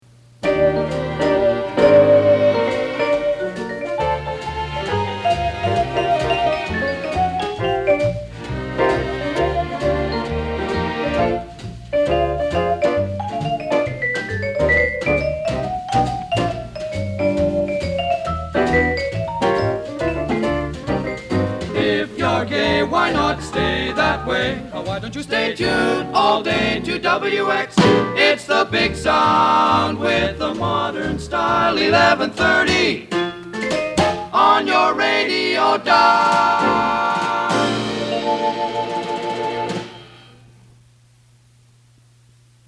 JINGLES AND MORE AUDIO